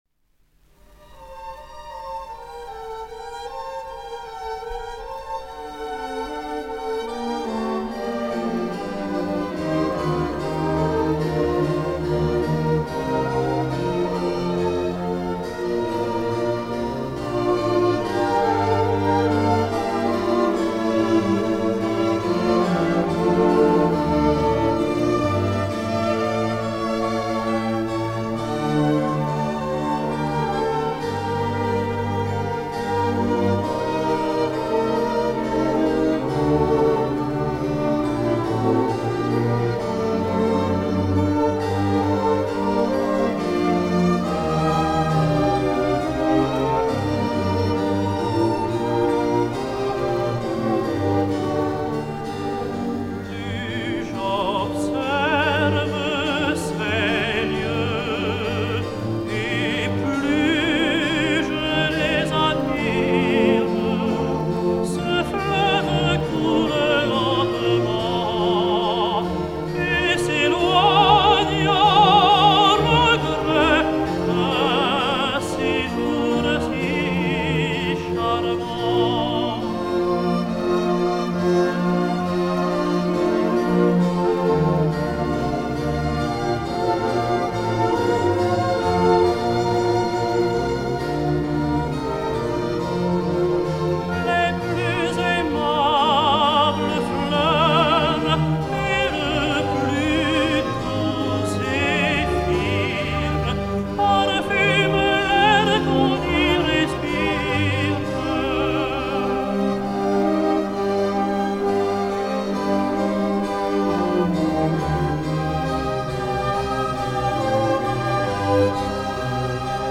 André Mallabrera singsArmide (Lully):